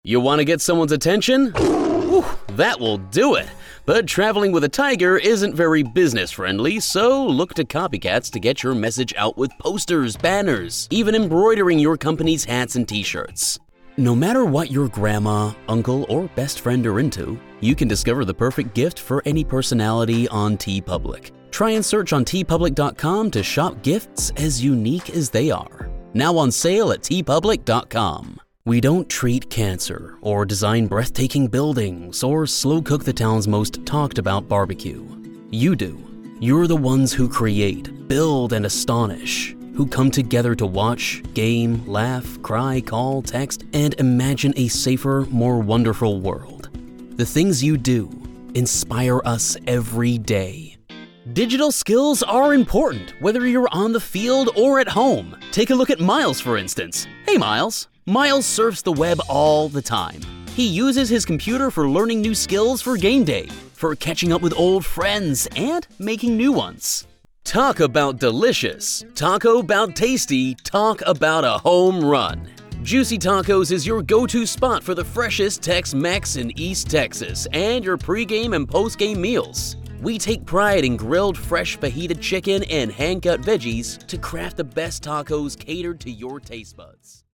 Bowel Cancer UK Voice Over Commercial Actor + Voice Over Jobs
English (British)
Yng Adult (18-29) | Adult (30-50)